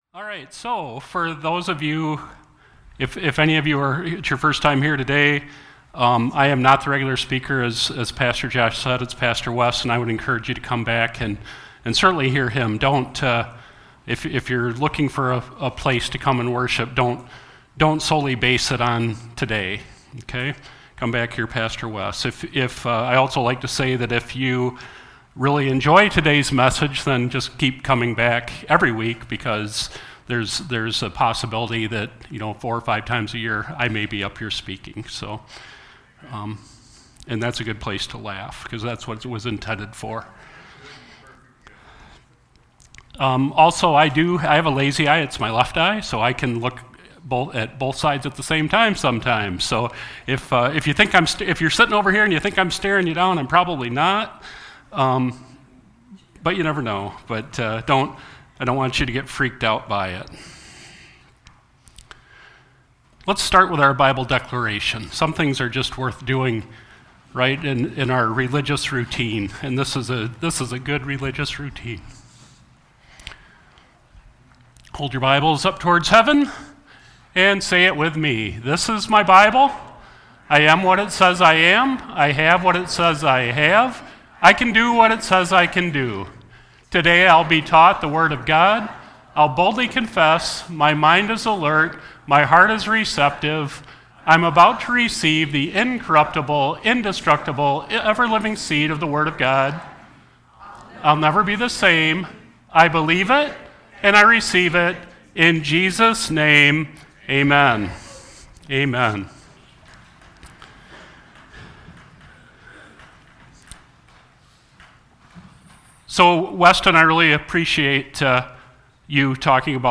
Sermon-3-29-26.mp3